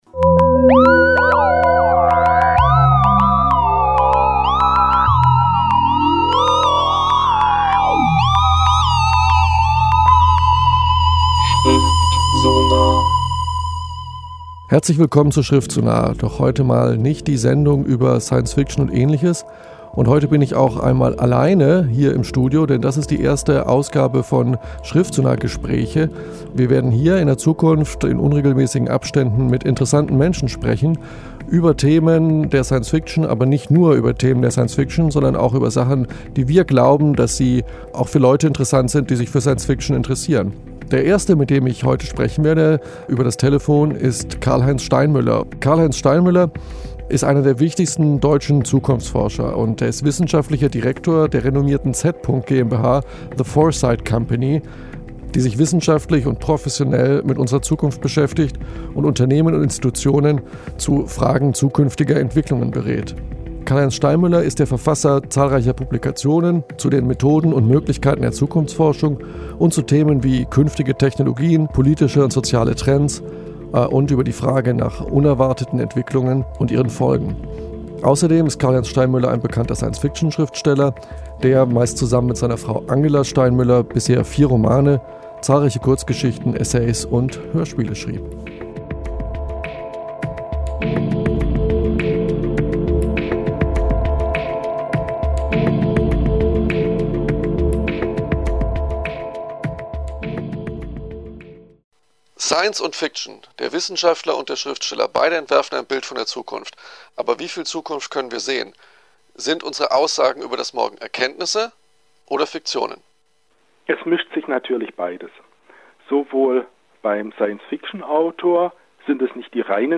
Interview: Die Erforschung der Zukunft
Der Zukunftsforscher und Schriftsteller Karlheinz Steinmüller im Gespräch mit Schriftsonar.
Wir greifen zum Telefon und reden […]
schriftsonar_steinmueller_interview.mp3